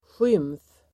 Uttal: [sjym:f]